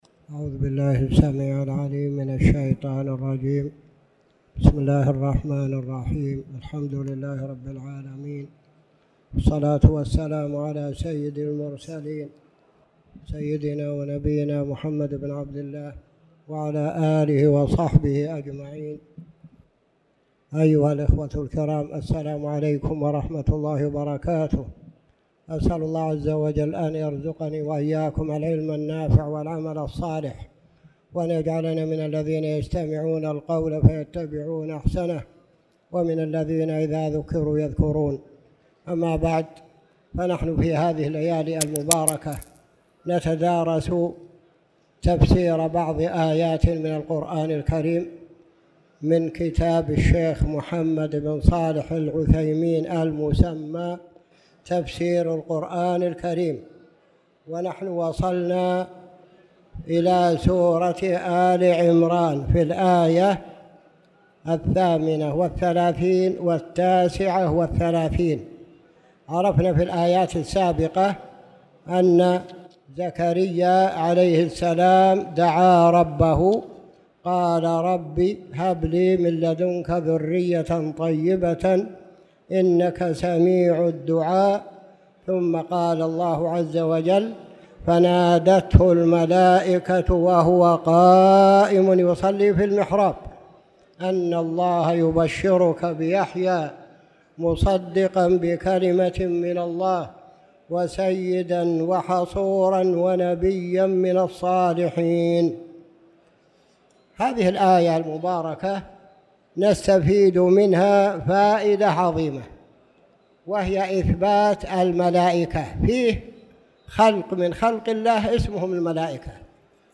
تاريخ النشر ١٦ جمادى الأولى ١٤٣٨ هـ المكان: المسجد الحرام الشيخ